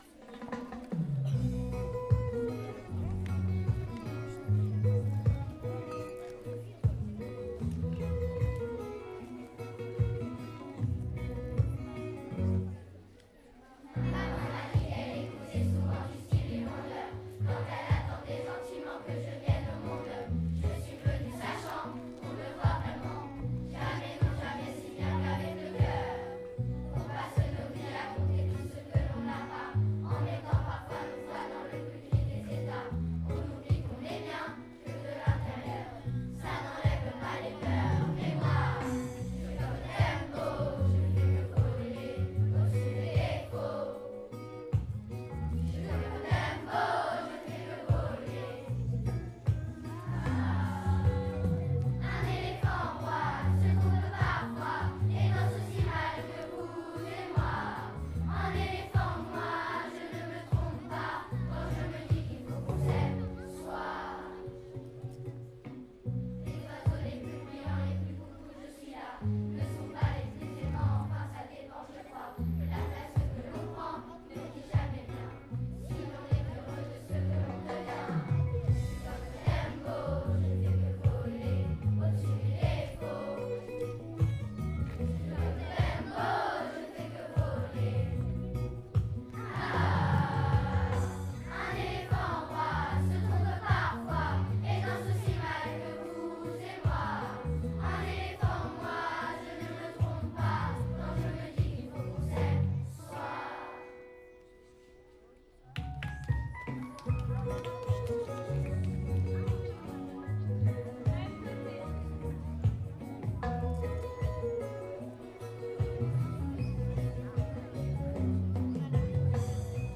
Kermesse de l’école 2022
L’ensemble des élèves de l’école Camille Hirtz ont présenté un spectacle (chants et danses) à l’occasion de la kermesse de fin d’année.